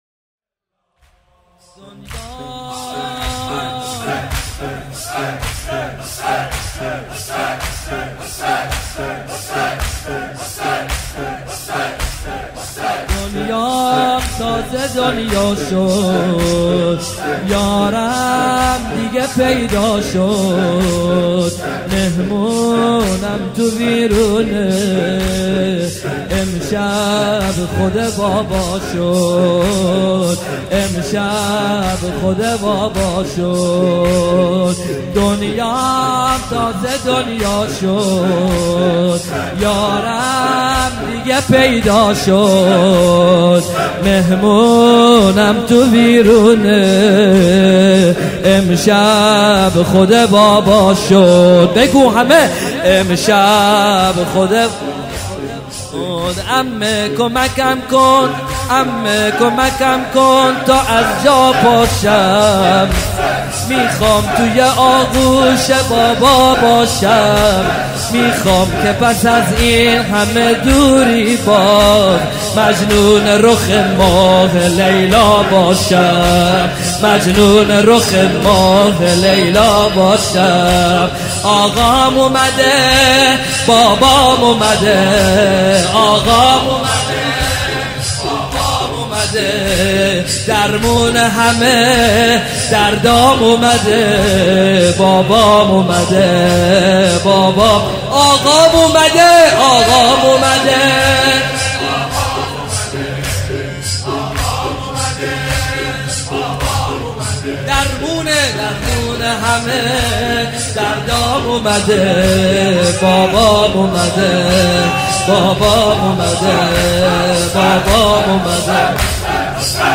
زمینه | دنیام تازه دنیا شد، یارم دیگه پیدا شد
مداحی
شب 3 محرم سال 1439 هجری قمری